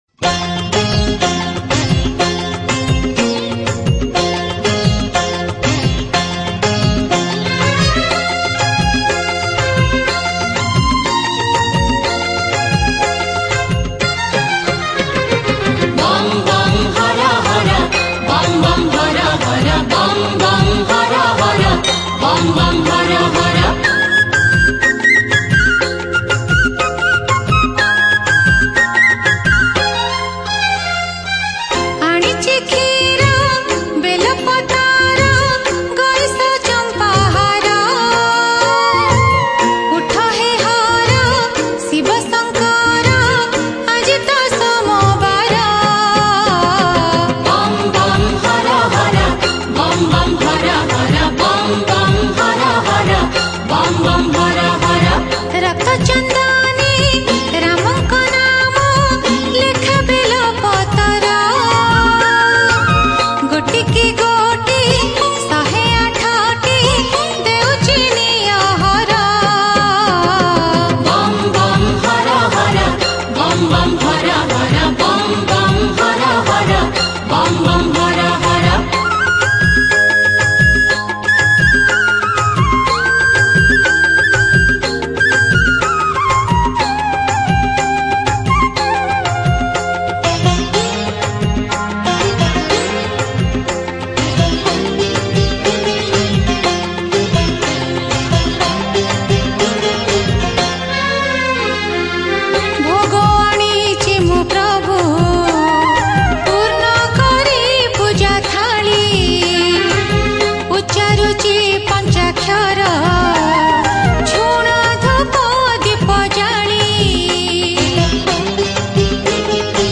Jagara Special Odia Bhajan Song Upto 2021 Songs Download